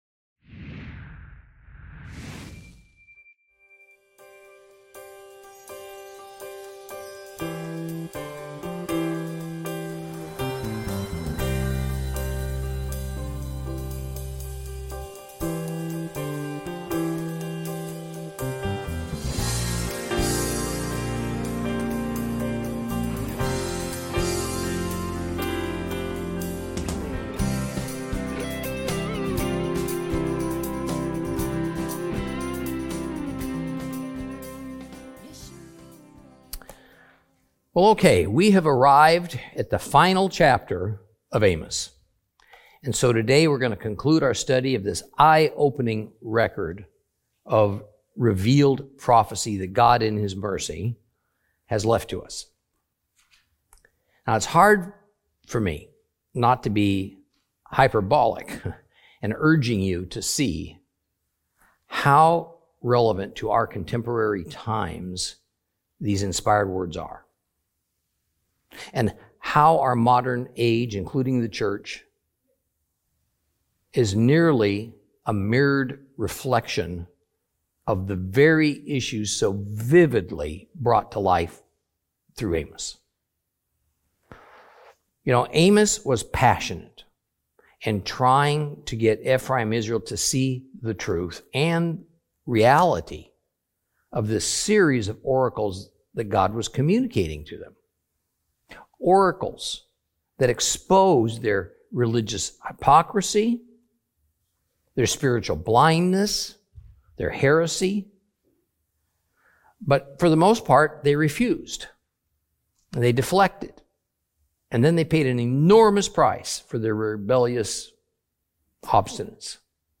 Teaching from the book of Amos, Lesson 14 Chapter 9.